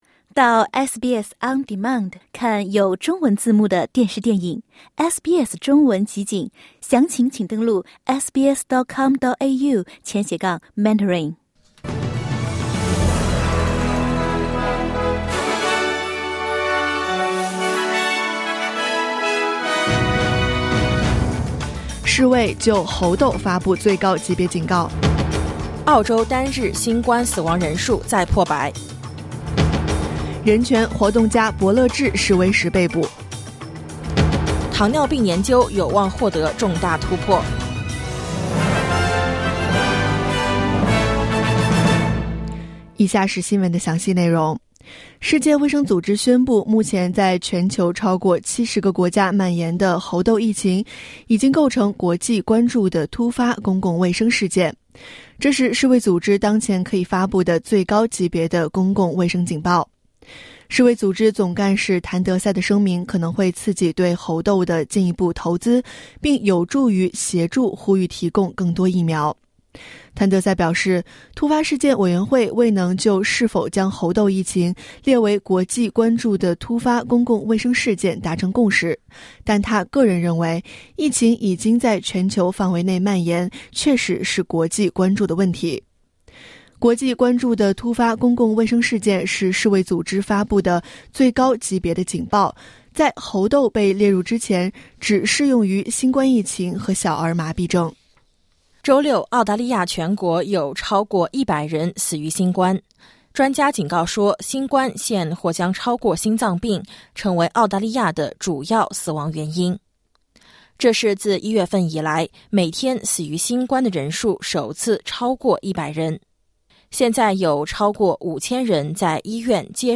SBS早新闻（2022年7月24日）